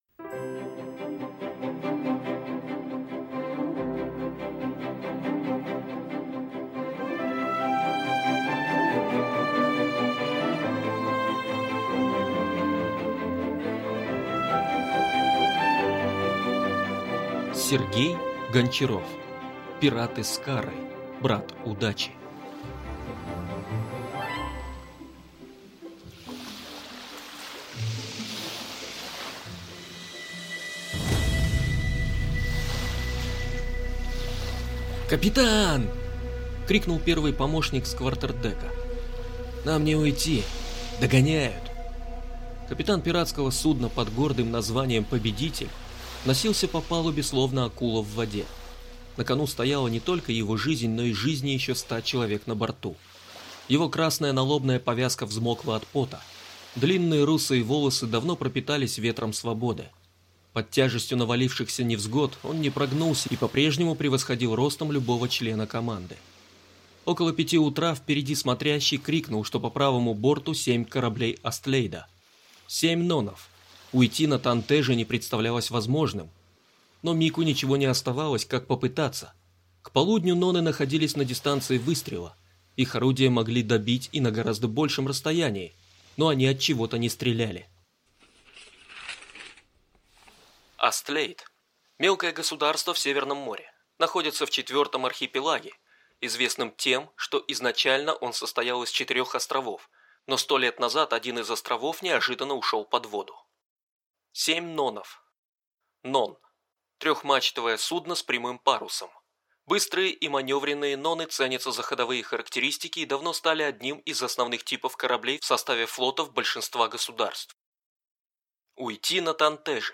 Aудиокнига Пираты Скарры. Брат Удачи